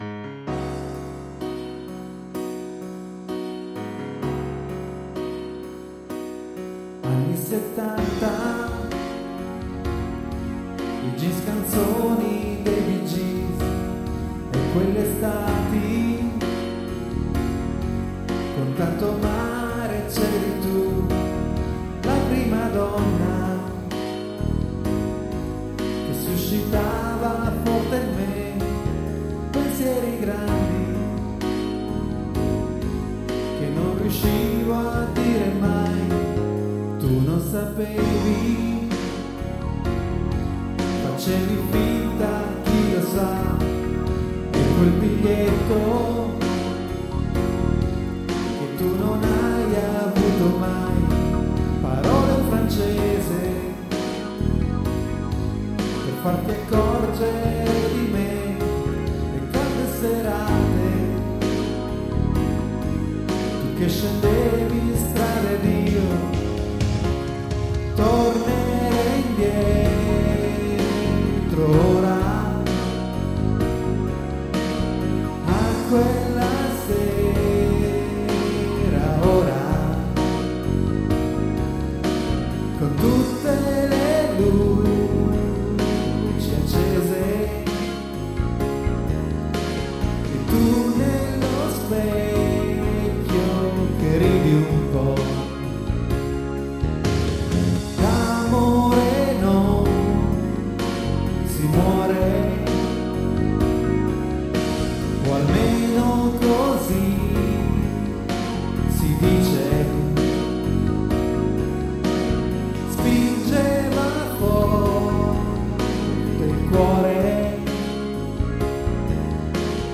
• Multitrack Recorder Zoom MRS-4
• Mic AKG D 40 S